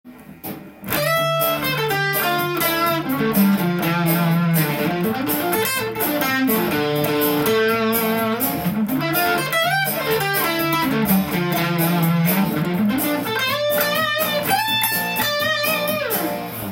代理コードを入れて知的に攻める
DmのところにFM7
B♭のところにDm7
を想定して似せたコードを追いながらギターソロを弾いていきます。